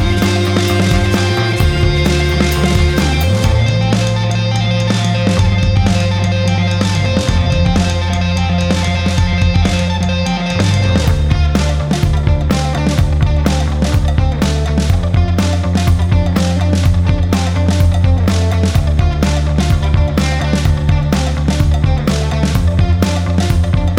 No Backing Vocals Rock 3:26 Buy £1.50